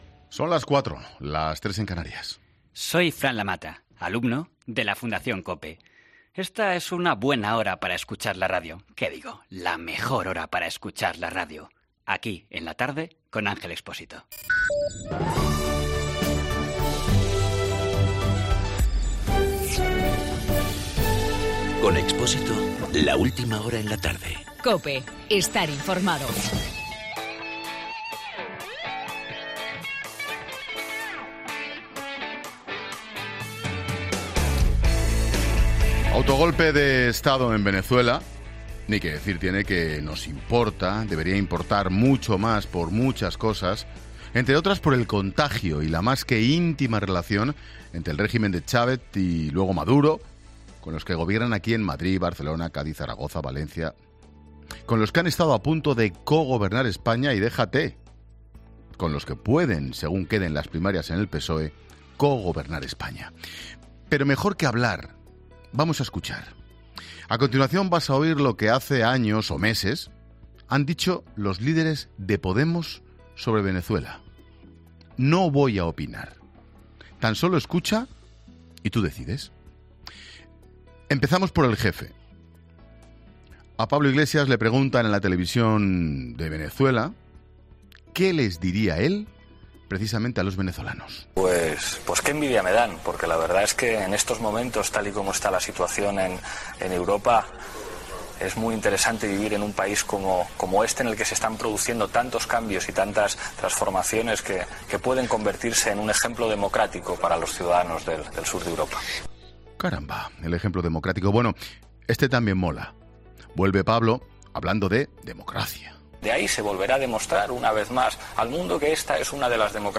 AUDIO: Monólogo 16 h.